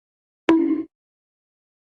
Bonk Sound effect
bang baseball bat bonk sound effect free sound royalty free Sound Effects